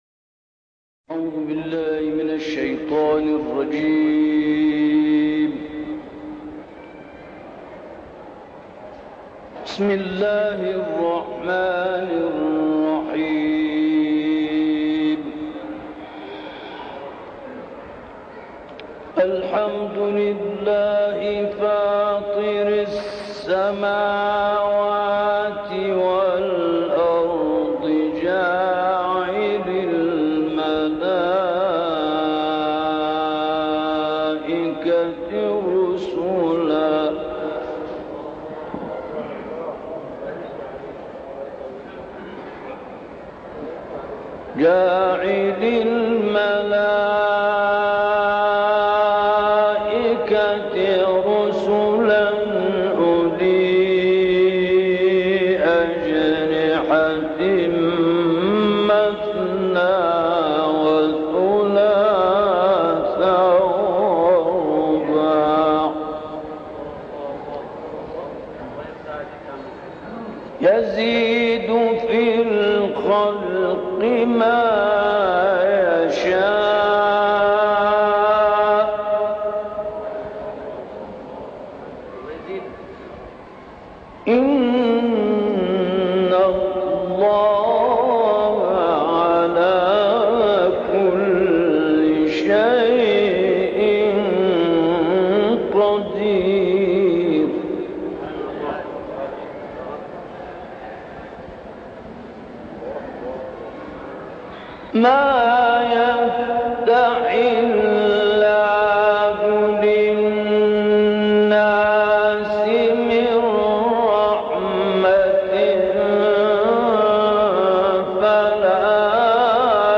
تلاوتی از شیخ شبیب + دانلود/ سخن از نشانه‌هاى خداوند در عالم هستى
گروه فعالیت‌های قرآنی: شاهکاری از تلاوت شیخ محمد احمد شبیب از آیات ۱-۱۵ سوره فاطر ارائه می‌شود.